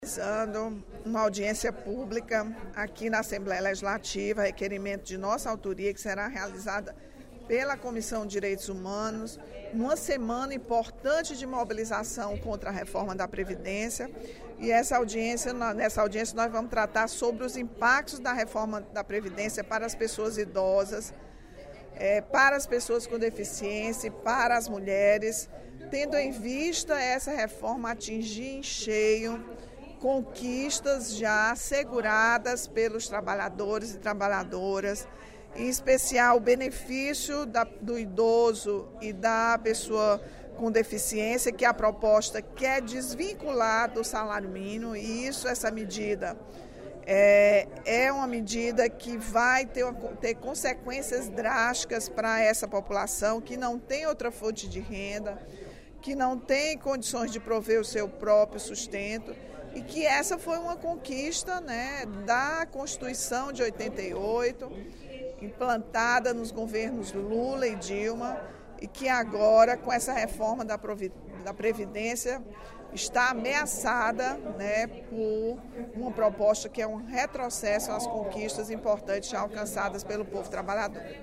A deputada Rachel Marques (PT) informou, durante o primeiro expediente da sessão plenária desta terça-feira (28/03), que uma audiência pública na Assembleia vai debater os impactos da proposta de Reforma da Previdência para mulheres, idosos e pessoas com deficiência.